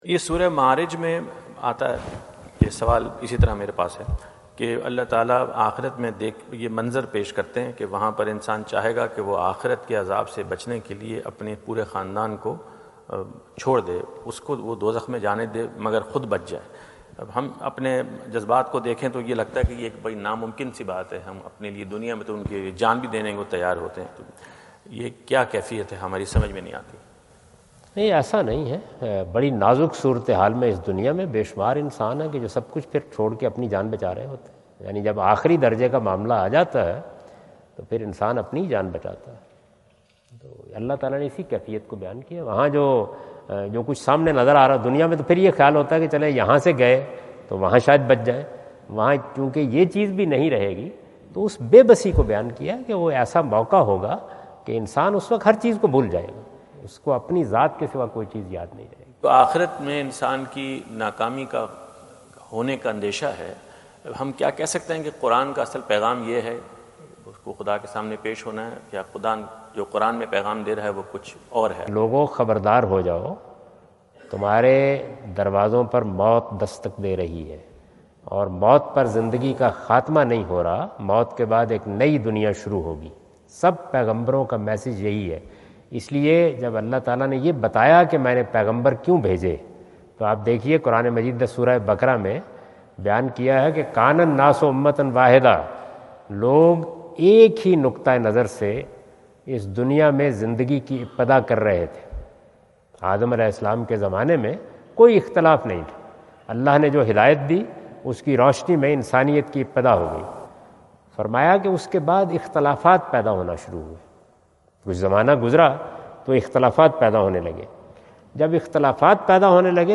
Javed Ahmad Ghamidi answer the question about "What is the meaning of Verse of surah e Ma’rij?" During his US visit at Wentz Concert Hall, Chicago on September 23,2017.